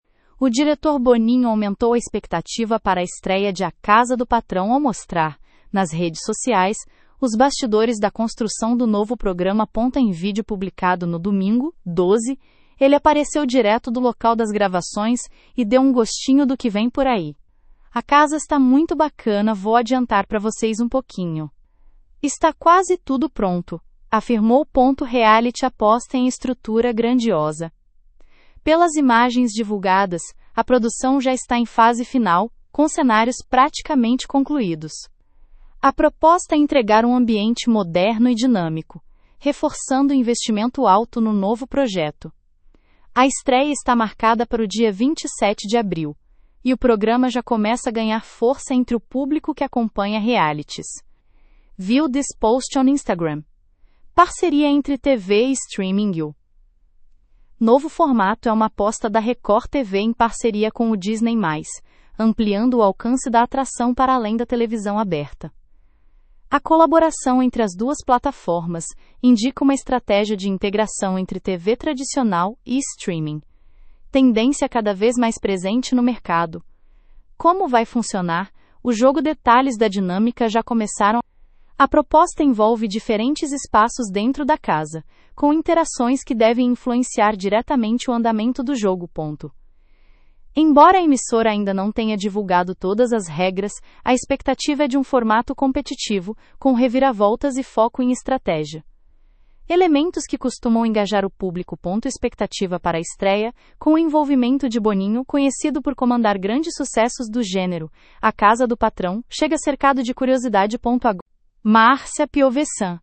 Em vídeo publicado no domingo (12), ele apareceu direto do local das gravações e deu um gostinho do que vem por aí.